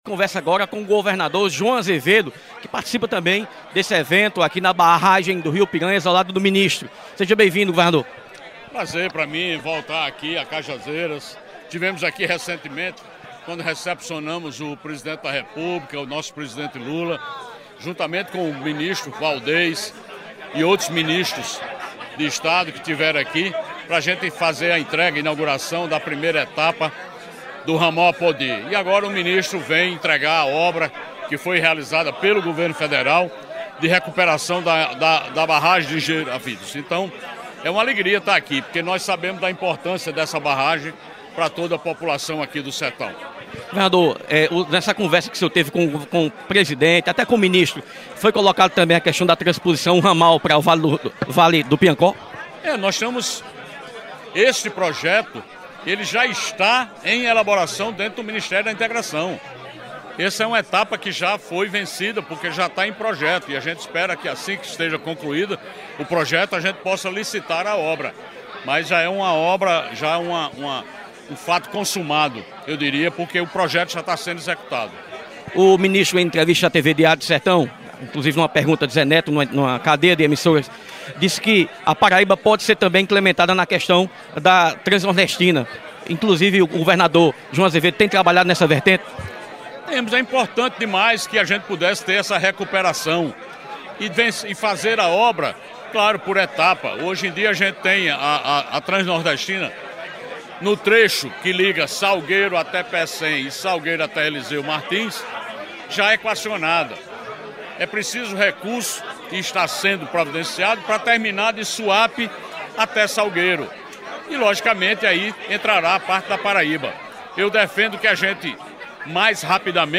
Entre os assuntos abordados estão: Novos projetos: Ramal do Vale do Piancó e Transnordestina; Investimentos e voos regionais; Saúde; Política e futuro e Orçamento Democrático em julho. Acompanhe a entrevista do Governador João Azevêdo a imprensa local:
02-Joao-Azevedo-Entrevista.mp3